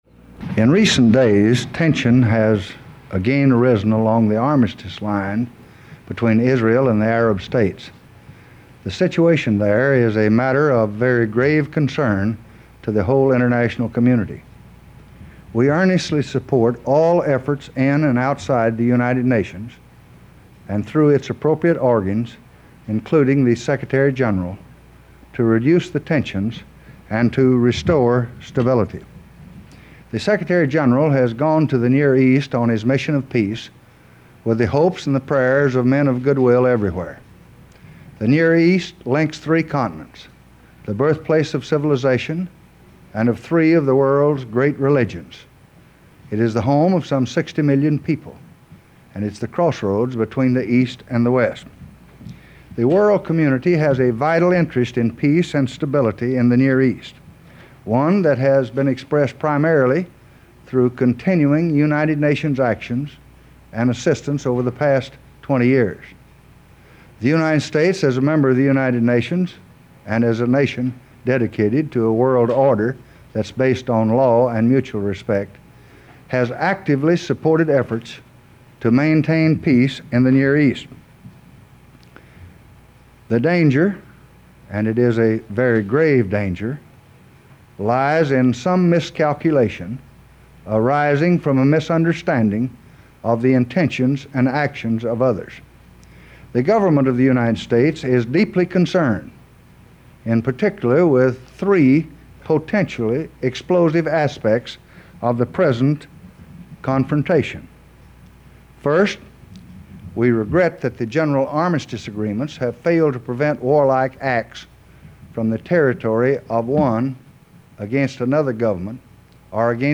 Statement on Rising Tensions in the Middle East
delivered 23 May 1967, White House, Washington, D.C.